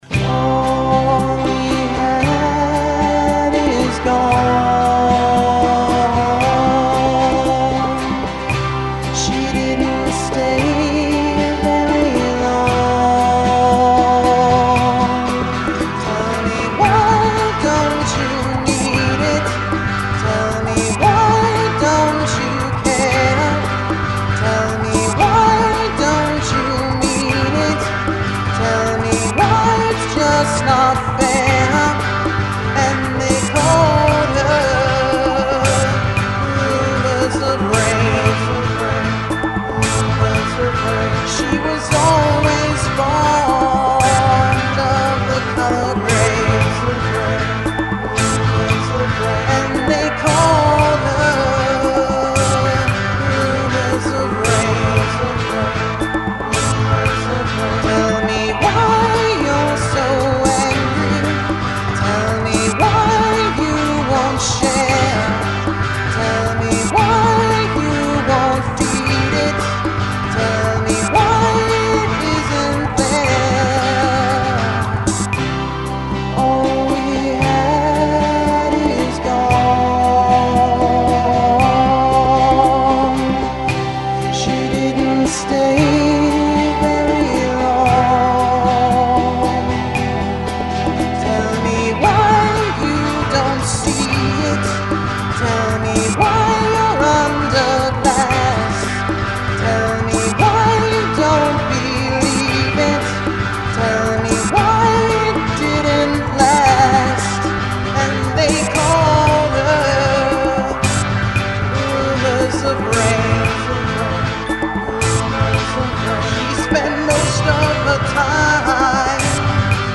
It is kind of mean and sad at the same time
the song is just catchy